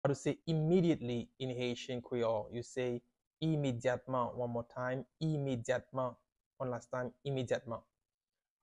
“Immediately” in Haitian Creole – “Imedyatman” pronunciation by a native Haitian teacher
“Imedyatman” Pronunciation in Haitian Creole by a native Haitian can be heard in the audio here or in the video below:
Immediately-in-Haitian-Creole-–-Imedyatman-pronunciation-by-a-native-Haitian-teacher.mp3